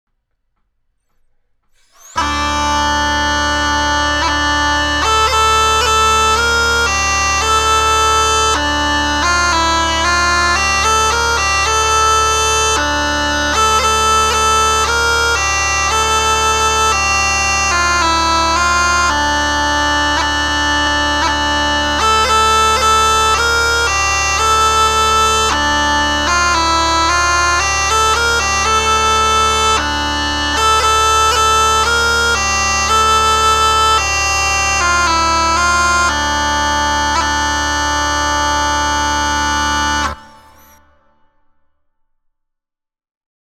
Mittelalter Dudelsack in tief C/d:
Bei diesem Dudelsack handelt es sich um ein sehr großes, tief klingendes Instrument.
Er hat eine sehr warme Klangfarbe bei einer moderaten bis kräftigen Lautstärke.
Tonumfang: C´-d´´
Tonart: C-Dur und d-moll
Klangbeispiel